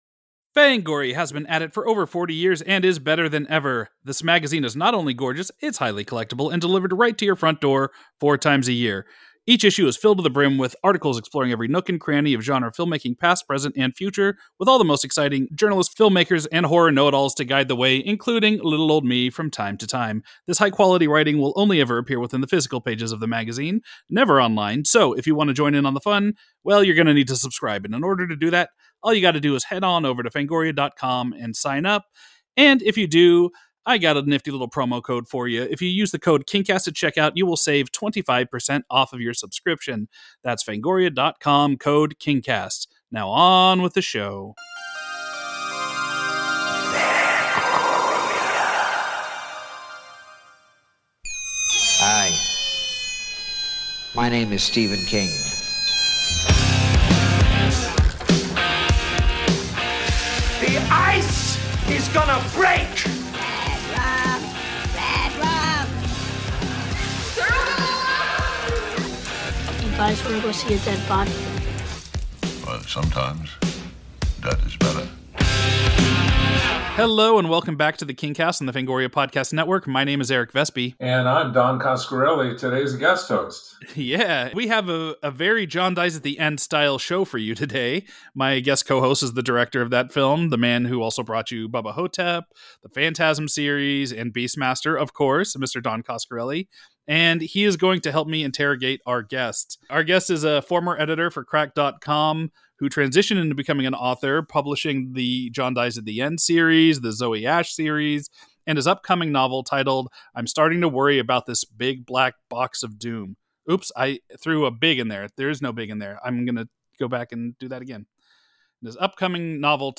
Unlike previous episodes on this title, King's novel is as the forefront this time around as these three gentlemen discuss the novel and talk about the things that work even better than the fantastic Rob Reiner adaptation.